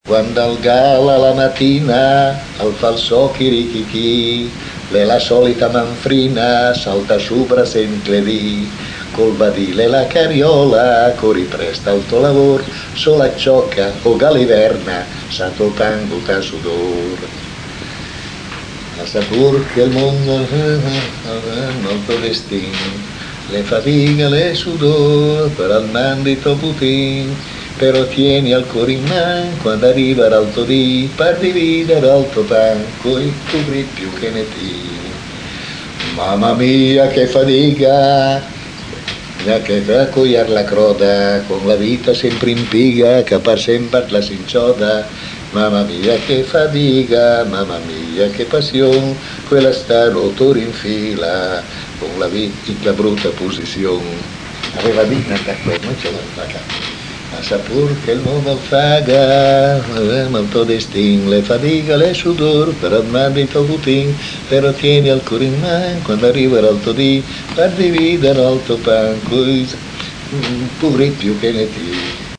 quand al gal a la matina(canzone sui braccianti).mp3